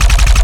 Added more sound effects.
GUNAuto_RPU1 C Loop_03_SFRMS_SCIWPNS.wav